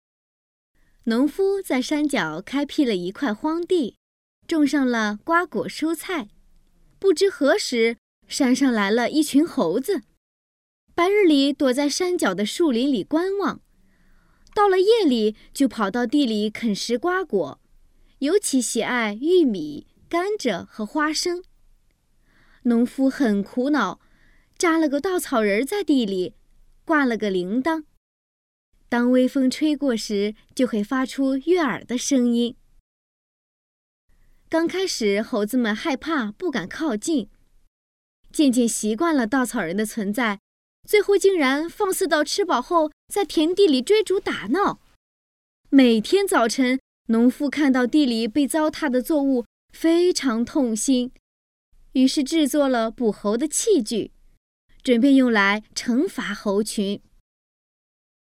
百字18元（女声列表）
擅长：专题片、宣传片、旁白、飞碟说MG、角色、朗诵等等.
说明：低价≠低质，以下老师均为中国传媒学院播音专业毕业，超高的性价比，高端设备，专业录音棚。